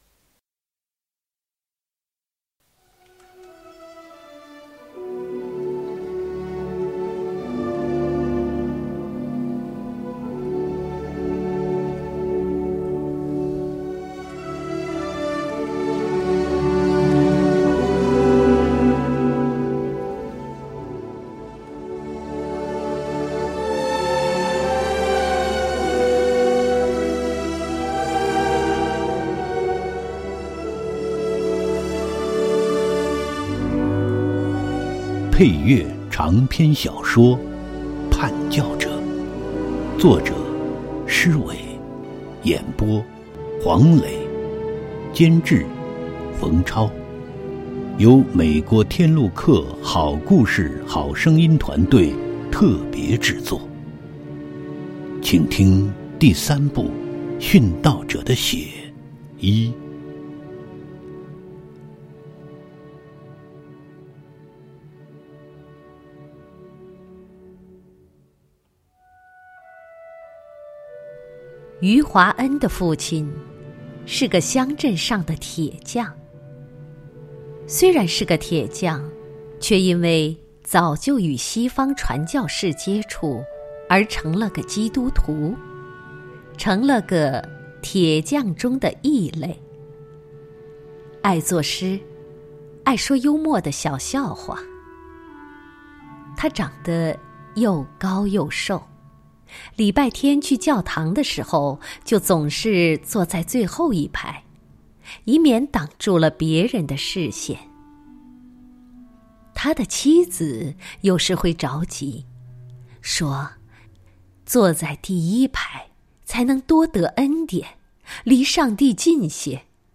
有声书连载：《叛教者》第三部《跟随者：殉道者的血》（第一章） | 普世佳音